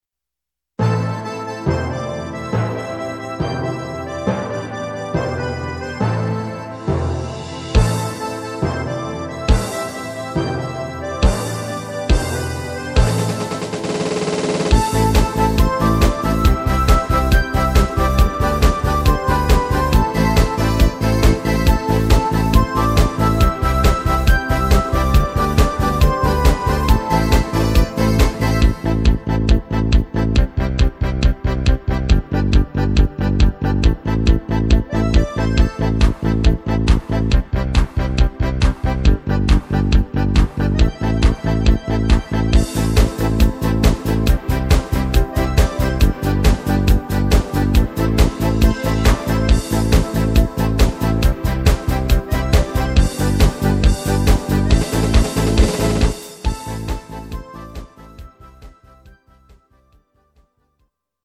Tanzmix Version